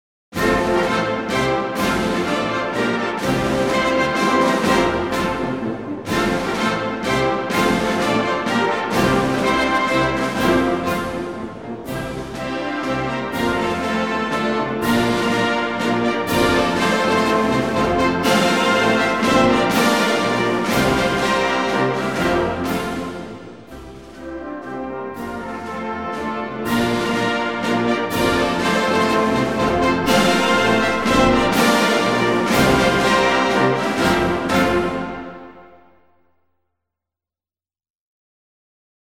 Музыка Гимна Польши в инструментальном исполнении